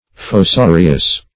Search Result for " fossorious" : The Collaborative International Dictionary of English v.0.48: Fossorious \Fos*so"ri*ous\, a. (Zool.)